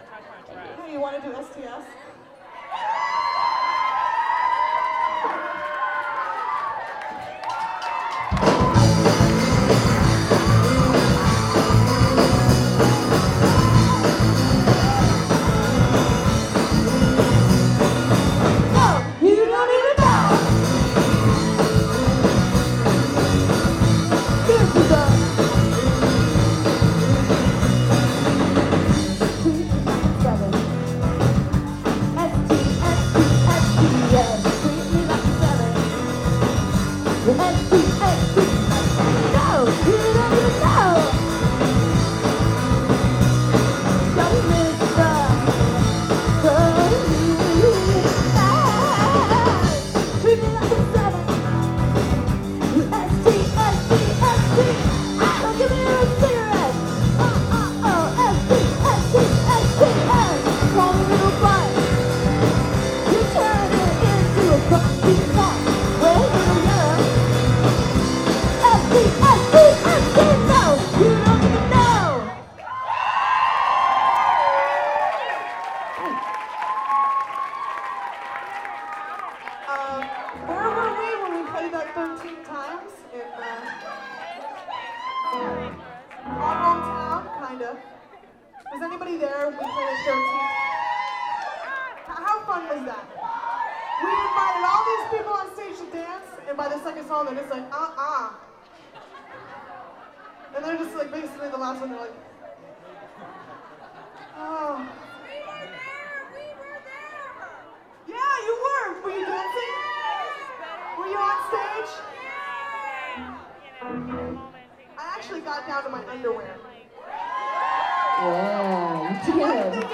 lifeblood: bootlegs: 2002-10-11: the echo lounge - atlanta, georgia (ladyfest) (amy ray and the butchies) (alternate)
02. talking with the crowd (3:05)